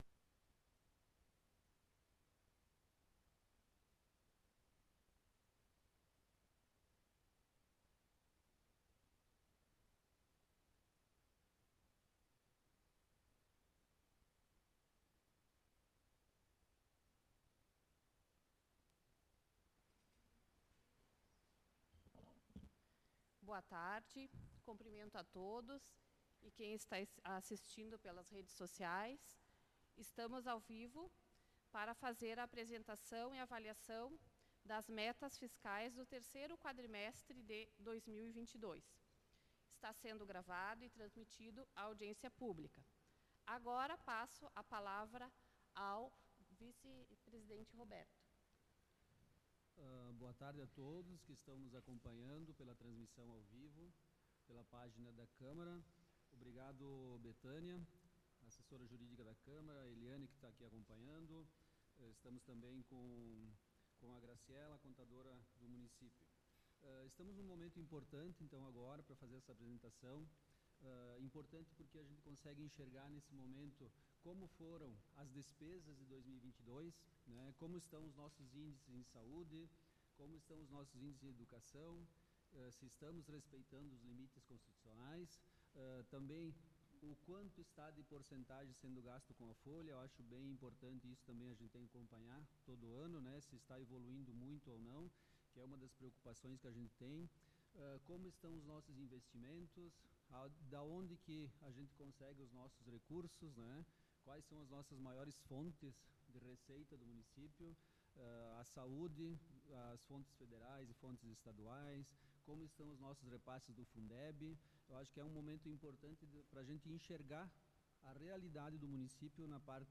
Audiência Pública
Câmara de Vereadores de Nova Roma do Sul